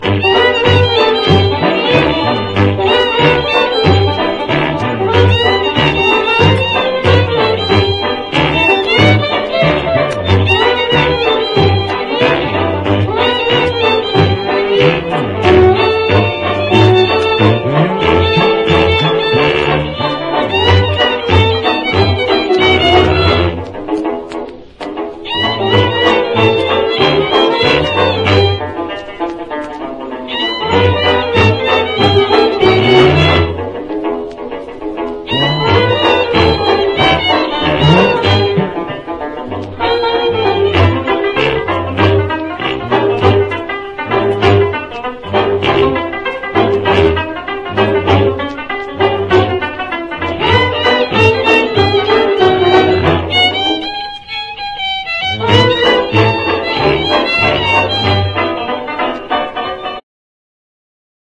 HIP HOP/R&B / ELECTRO (US)